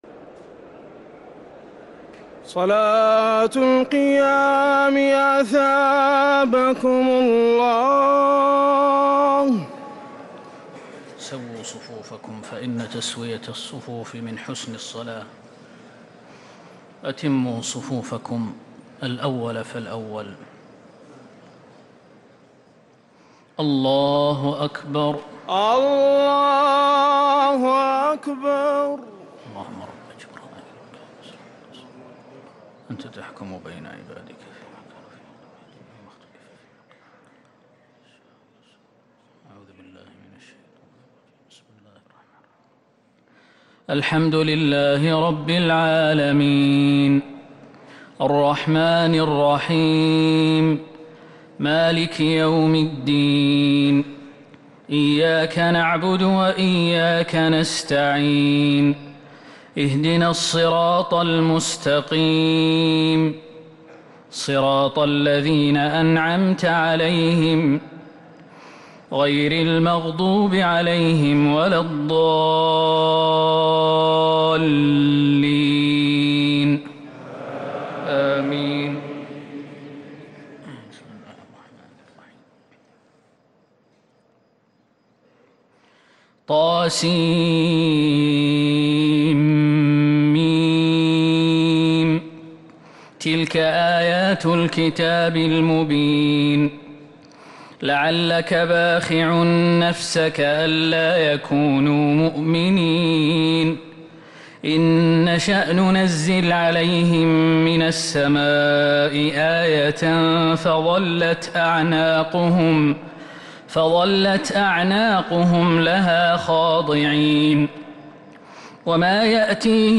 Ramadan Tarawih